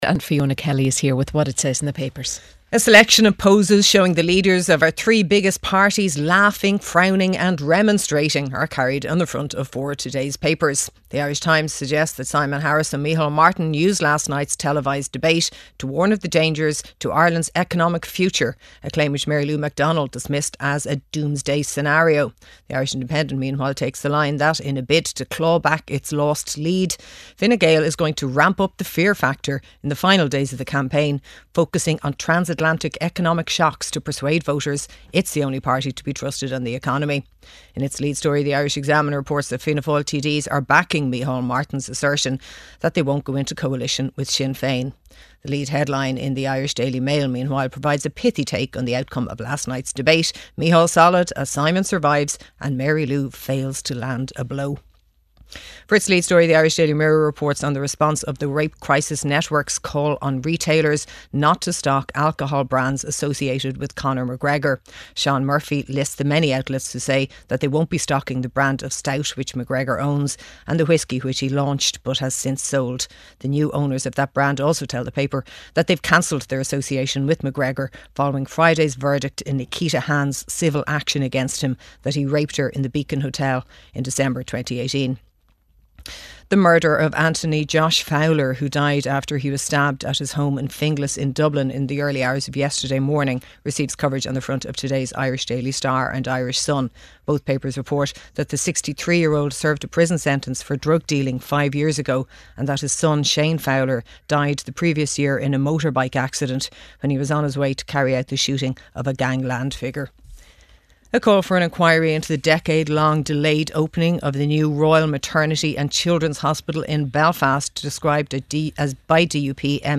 RTÉ's flagship news and current affairs radio programme and the most listened-to show in Ireland, featuring the latest news and analysis with Gavin Jennings, Audrey Carville, Áine Lawlor and Mary Wilson.